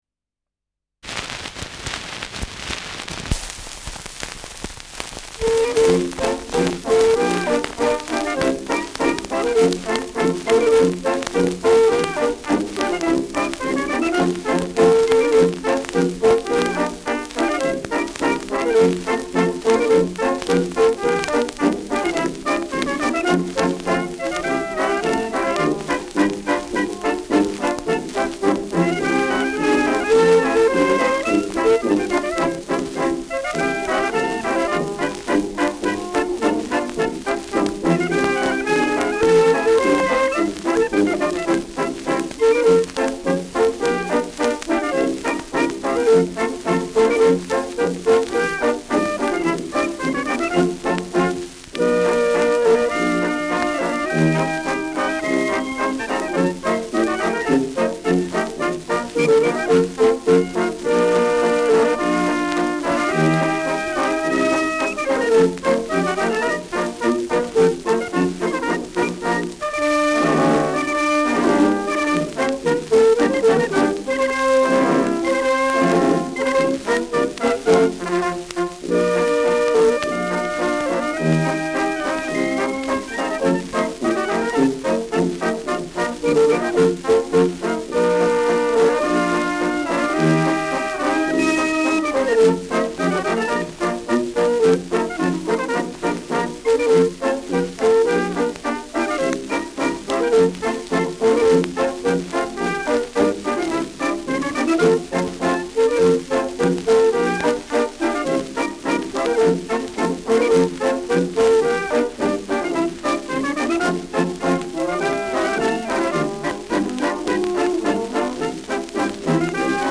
Schellackplatte
Stärkeres Grundrauschen : Durchgehend leichtes bis stärkeres Knacken